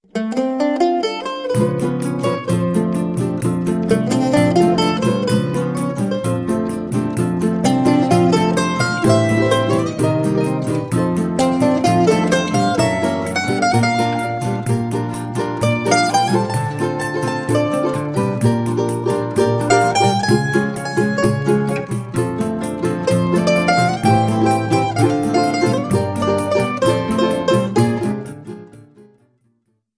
mandolin
Choro ensemble